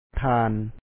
Pronunciation Notes 20
thāan Honorable